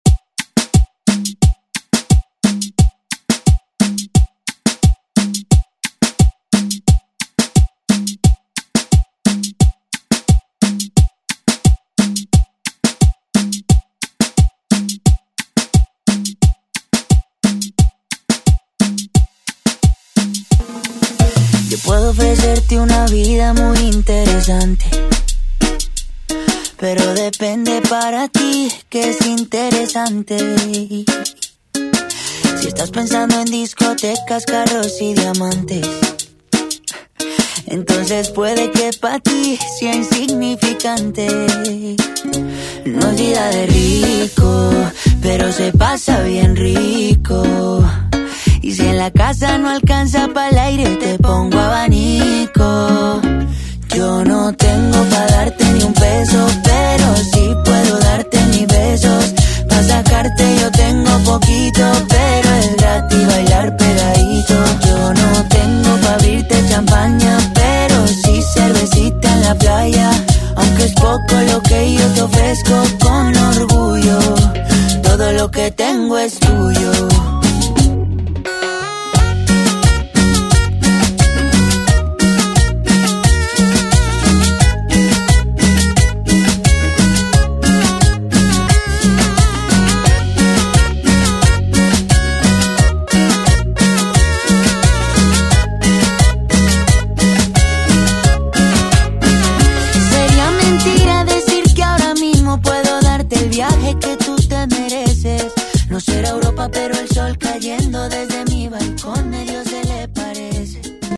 Throwback Hip Hop Rap Music Extended ReDrum Dirty 96 bpm
Genres: 2000's , HIPHOP , RE-DRUM
Dirty BPM: 96 Time